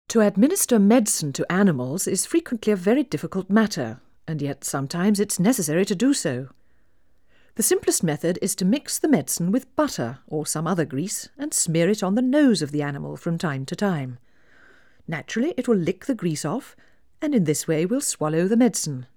Play 10dB SNR Noise BMLD
Noise out-of-phase
noise spatially separated from speech